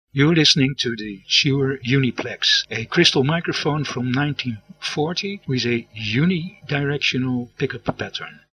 Since it did not pick up much of the ambient noise, the sound of the Uniplex was much cleaner than that of other low or medium priced microphones.
Shure Uniplex sound UK.mp3